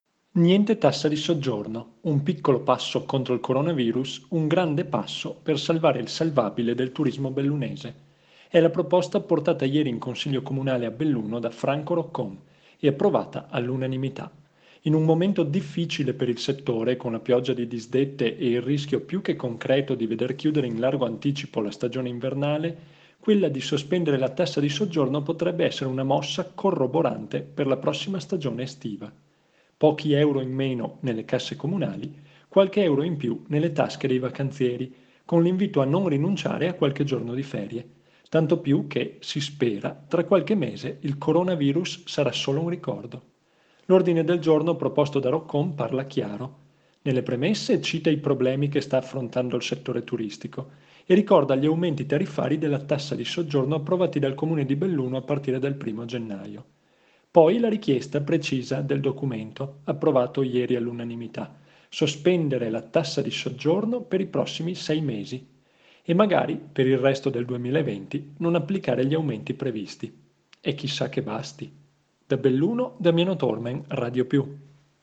audio dalla rassegna stampa delle otto